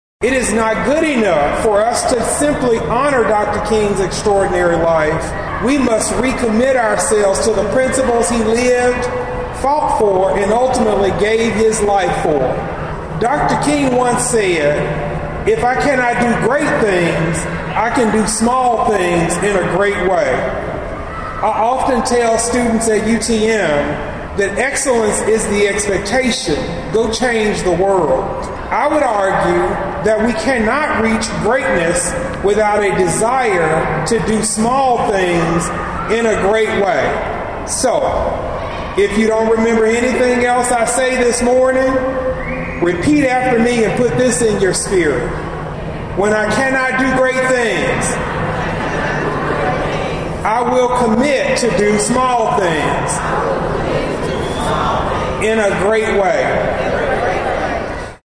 Discovery Park of America hosted an event on Monday in celebration of Dr. Martin Luther King Jr.